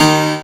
55y-pno14-a#4.wav